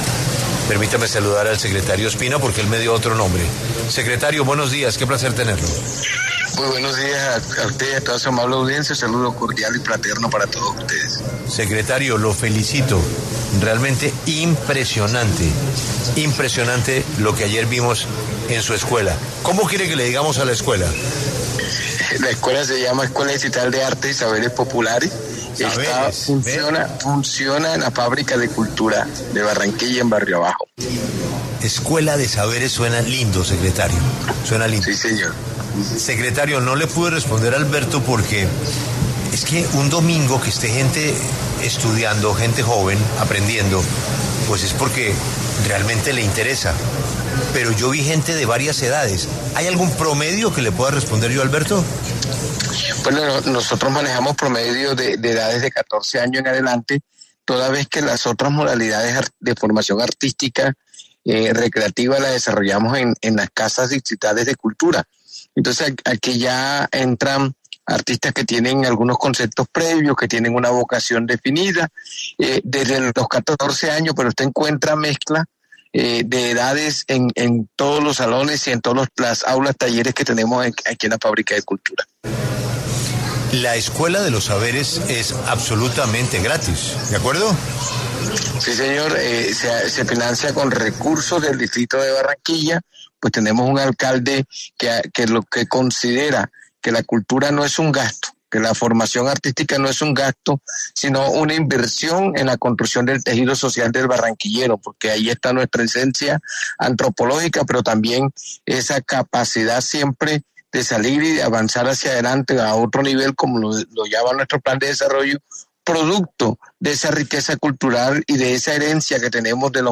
Juan Carlos Ospino, secretario de Cultura de Barranquilla, habló en La W sobre el trabajo que se hace en la Escuela Distrital de Artes y Saberes Populares.